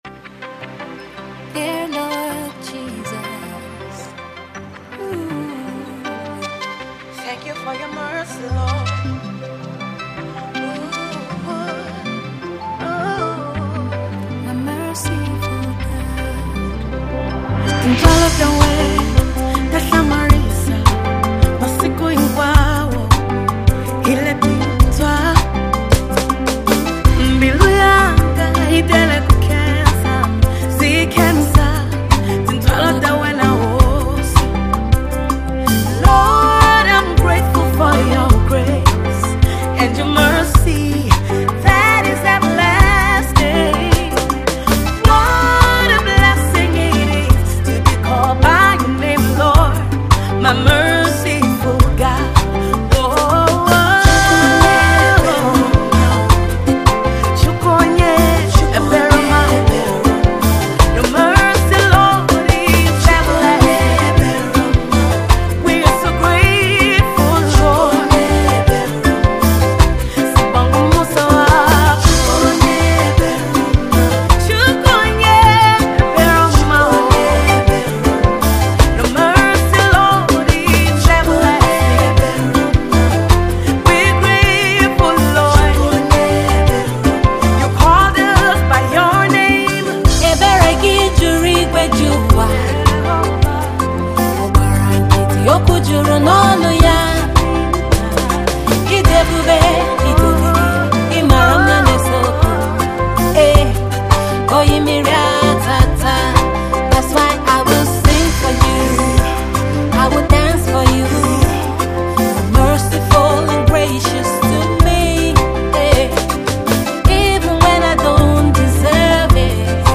soulful crooner
a mid-tempo African praise song done in English